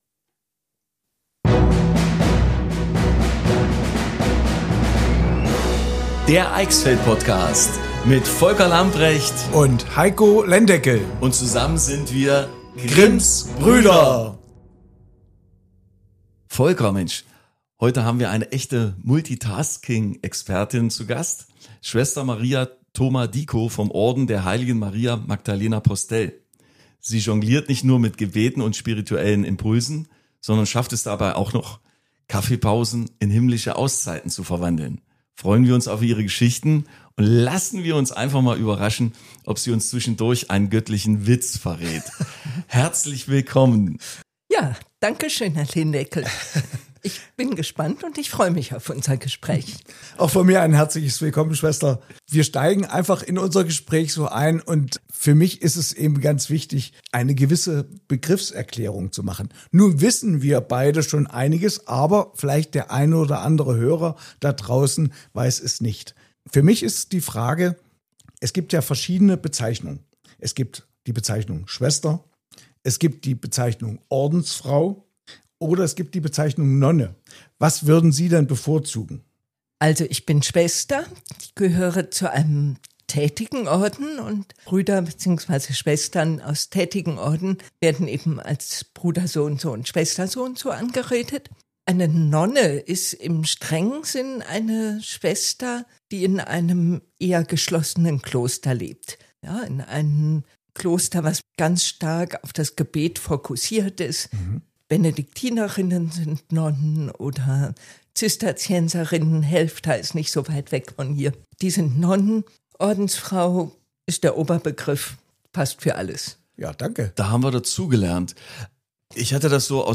Ein tiefgründiges Gespräch über Glauben, Verantwortung und die Kraft der Gemeinschaft – inspirierend, ehrlich und voller Zuversicht.